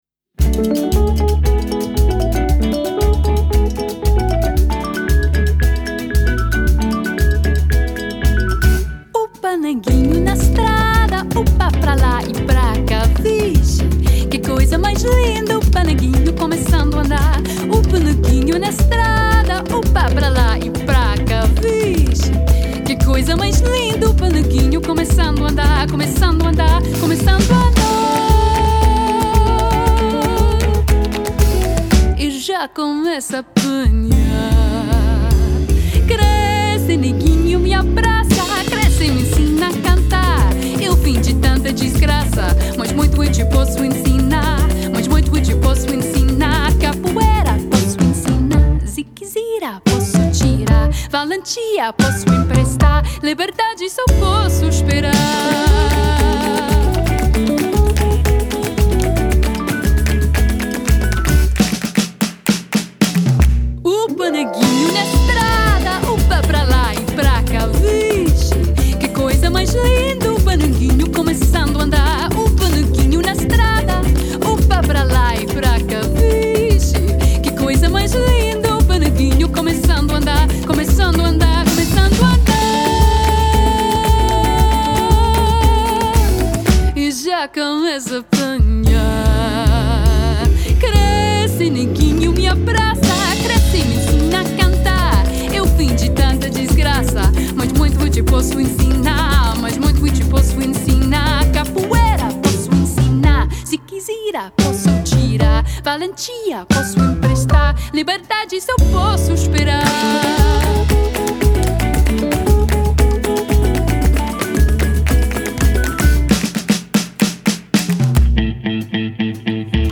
drumset, percussion, vocals
piano, Rhodes, vocals
guitar
bass
harmonica